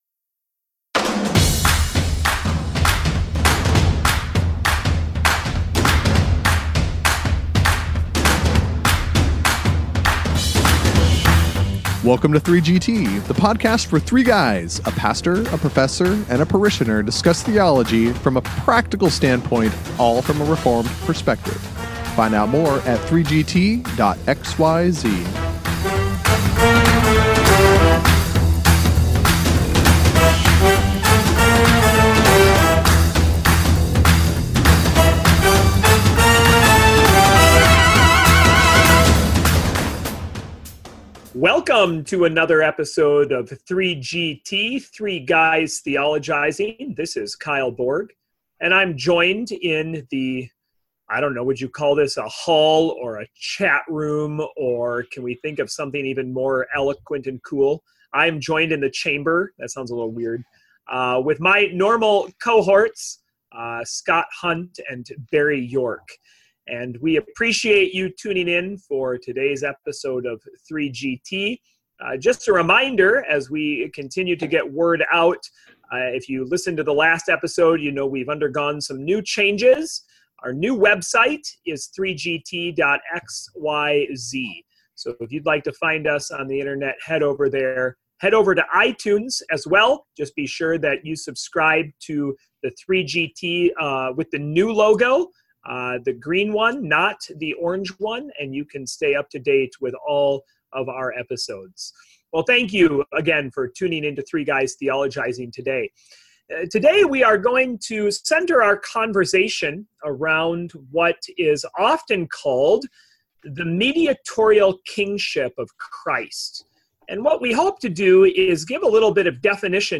The pastor leads the charge with all kinds of questions.
The professor and parishioner try their best to keep up, but are left a bit breathless by the end.
ep-97-mediatorial-kingship-cmd-mono-mix.mp3